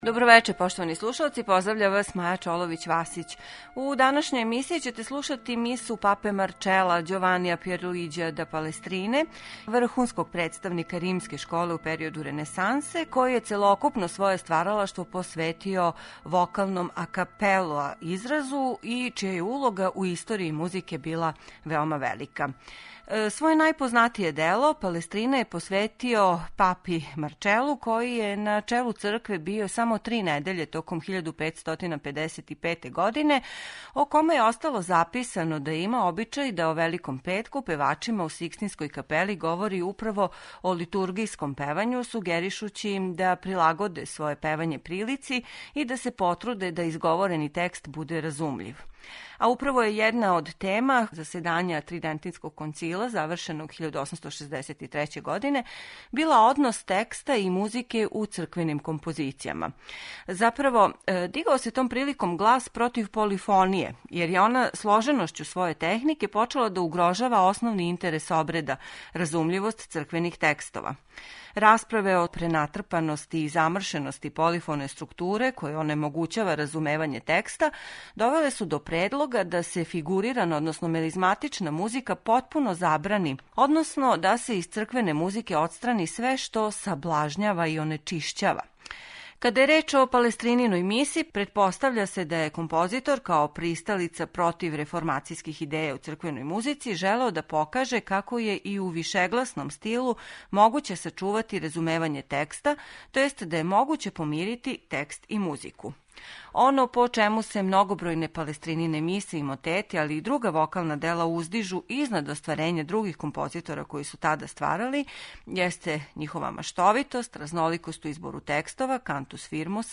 Наиме, овим делом, које се сматра прекрeтницом у историји музике, Палестрина је заправо показао како је и у вишегласном стилу могуће сачувати разумевање текста.
слушаћете ово дело у извођњу хора Вестминстерске опатије којим диригује Сајмон Престон.